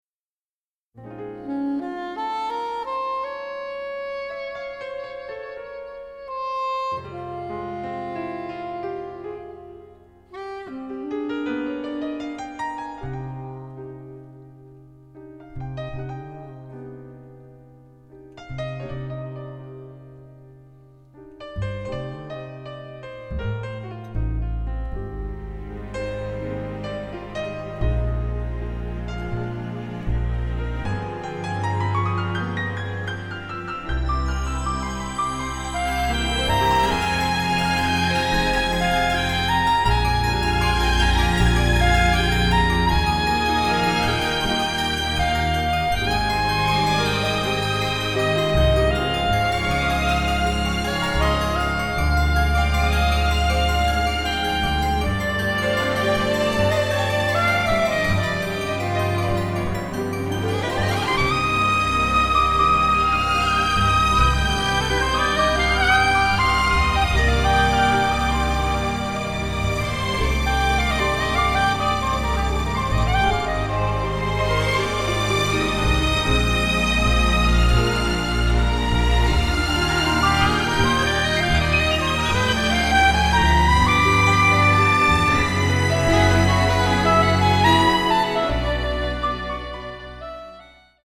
romantic noir score